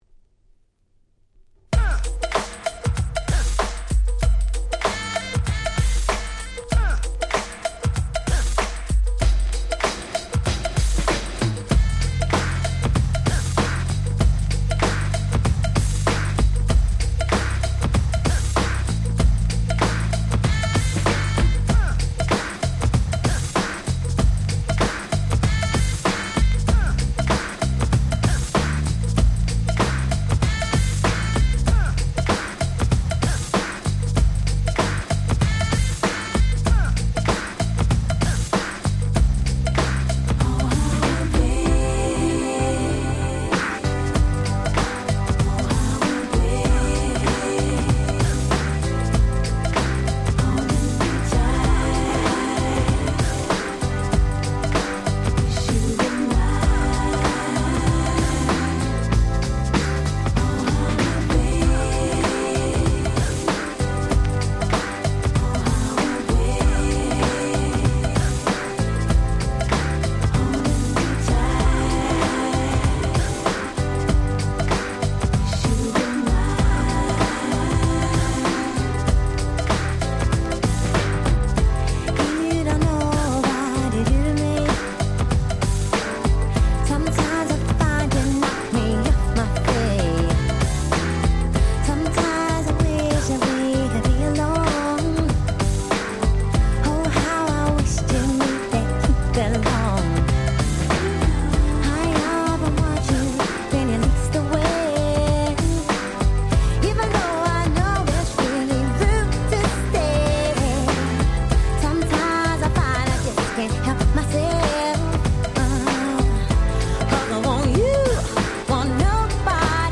【Media】Vinyl LP